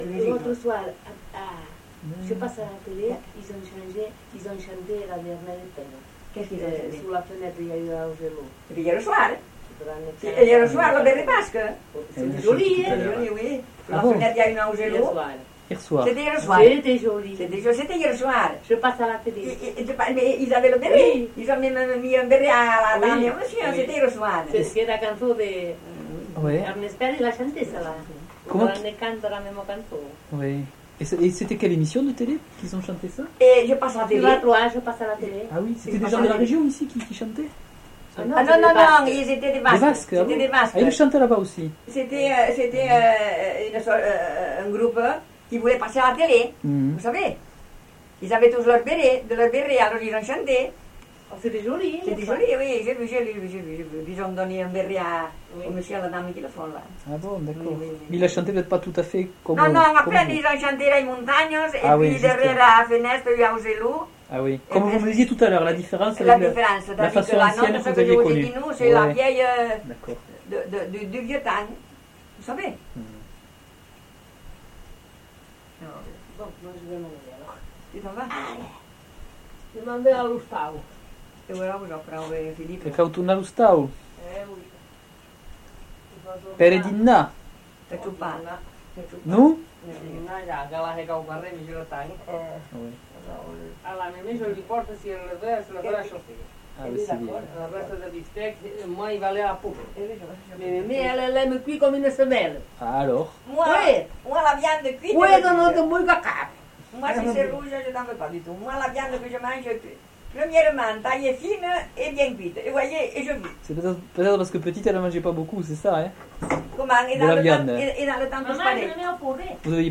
Discussion
Aire culturelle : Couserans
Lieu : Ayet (lieu-dit)
Genre : parole